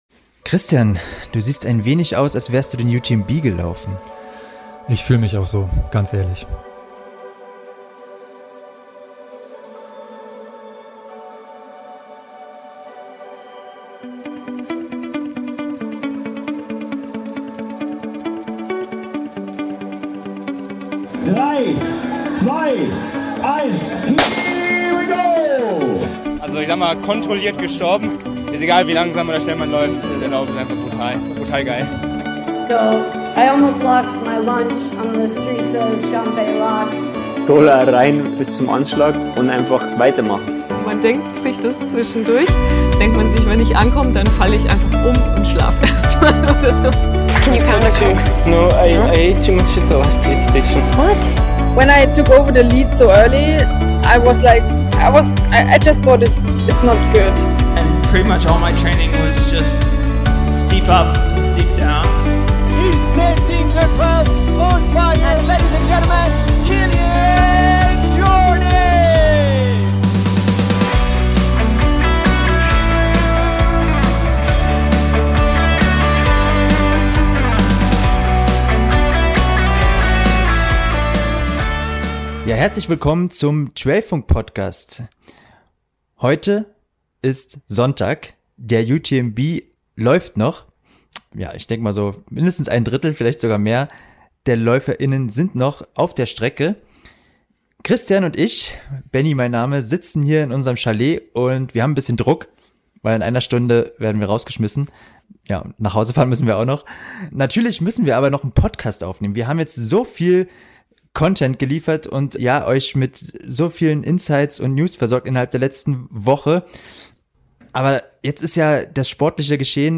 Obwohl: Sonntag früh, zum Zeitpunkt der Aufnahme dieser Folge, ist der UTMB noch nicht ganz vorbei.